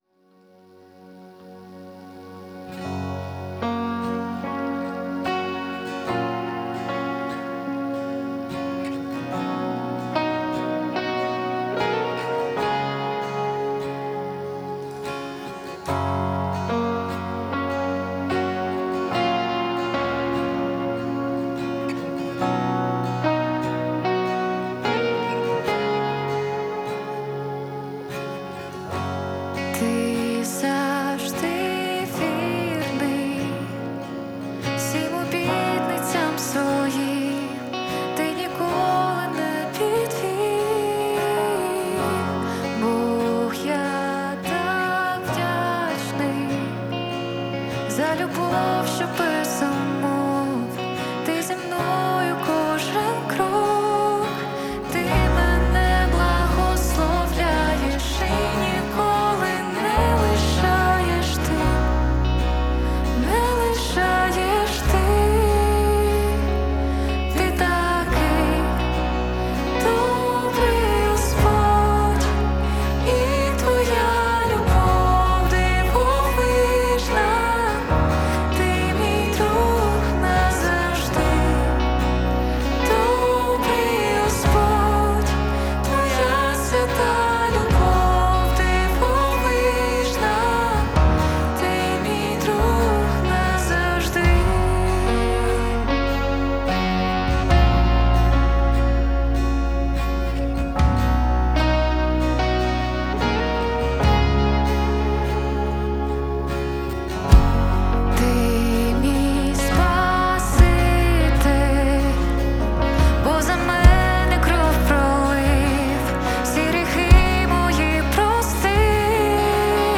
296 просмотров 5 прослушиваний 0 скачиваний BPM: 73